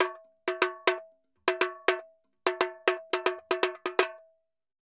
Tamborin Salsa 120_2.wav